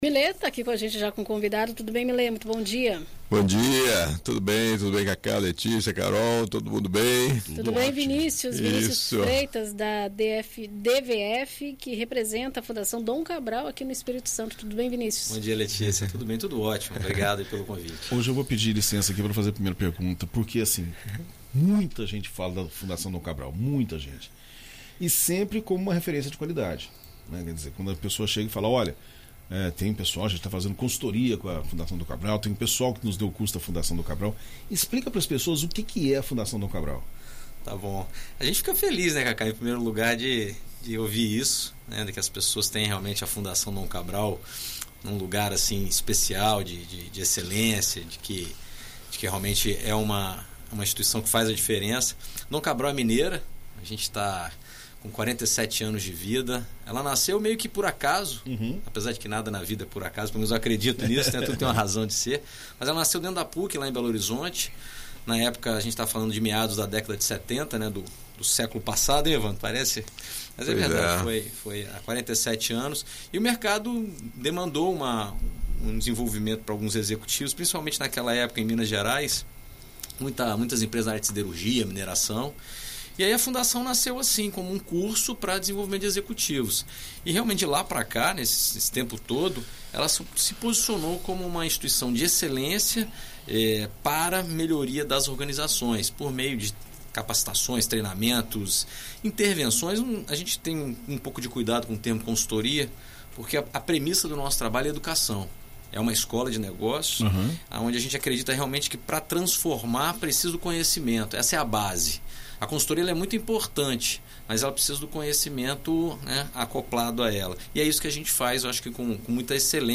na rádio BandNews FM Espírito Santo